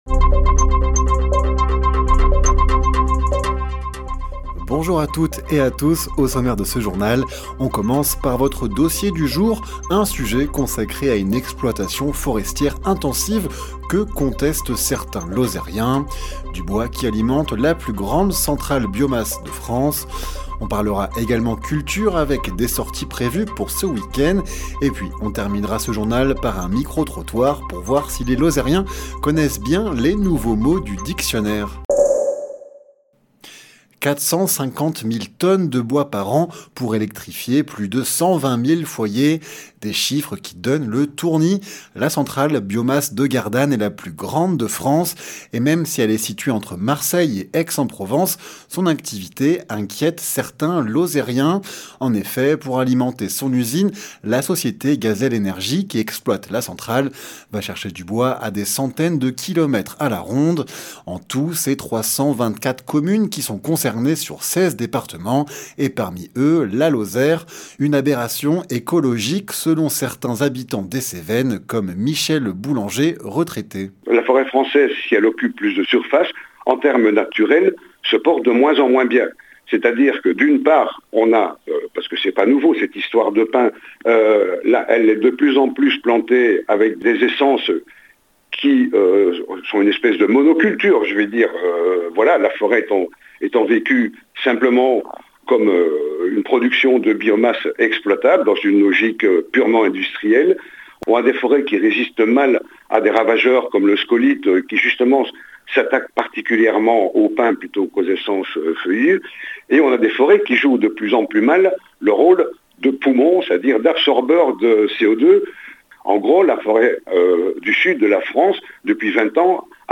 Les informations locales
Le journal sur 48FM présenté par